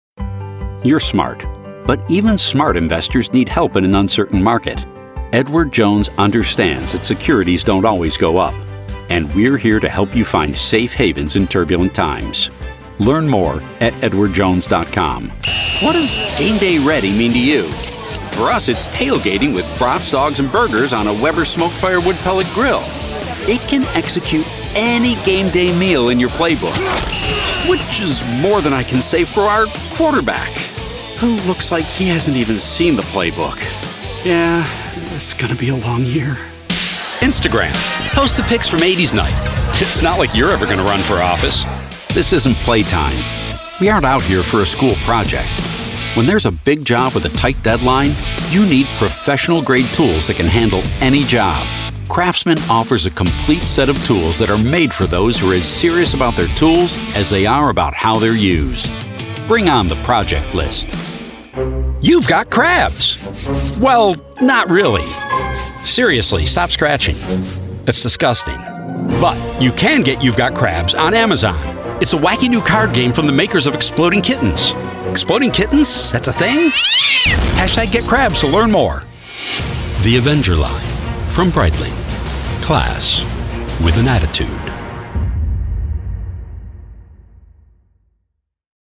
Easy-going, Real, Conversational.
Commercial